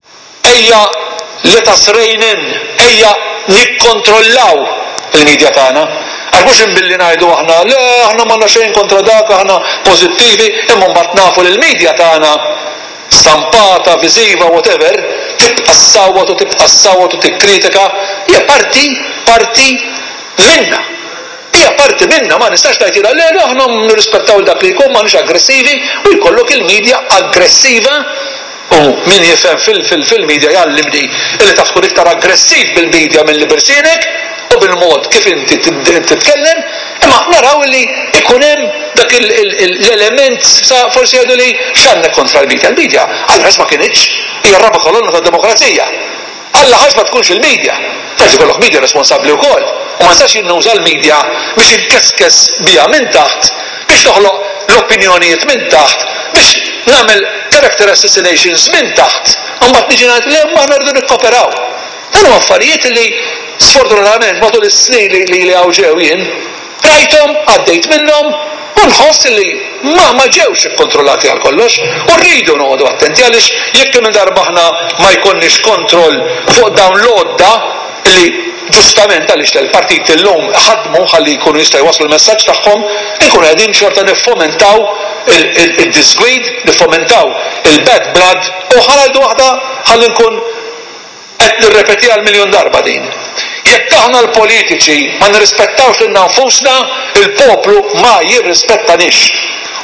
Foreign minister George Vella called for a more controlled media whilst speaking in parliament this evening.